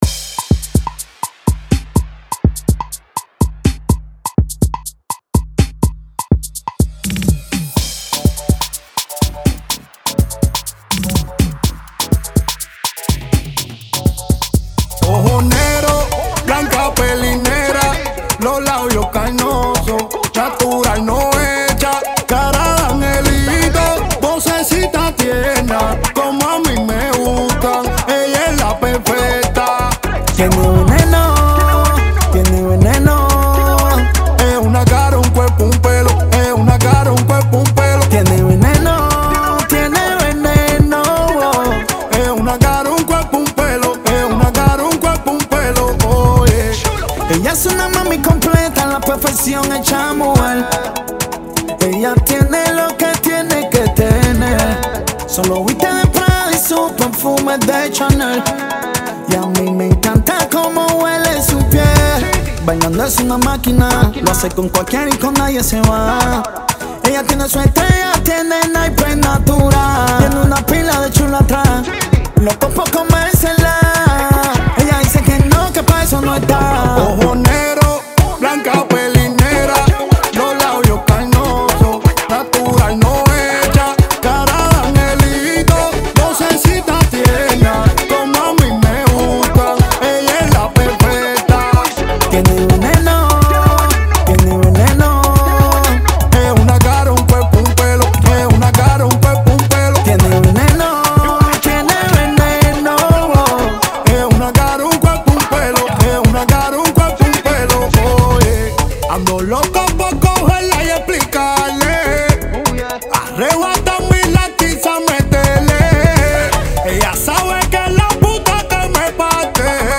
Genre: Blues.